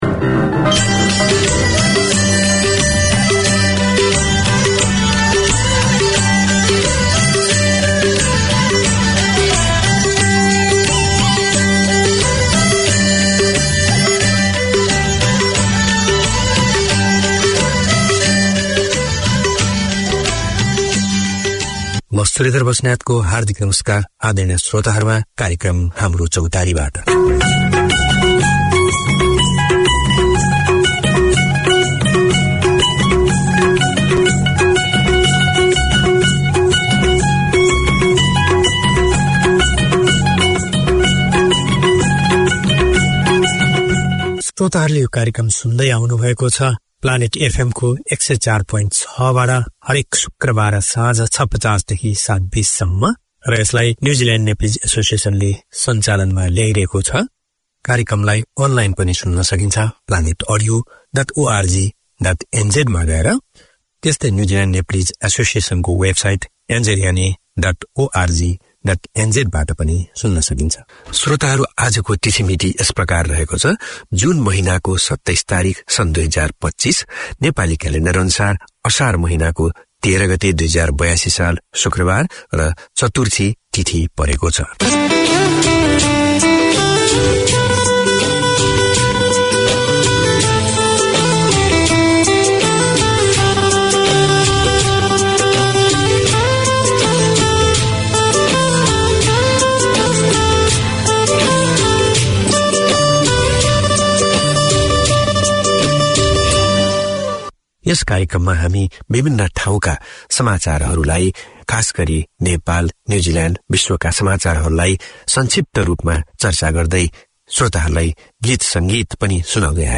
Radio made by over 100 Aucklanders addressing the diverse cultures and interests in 35 languages.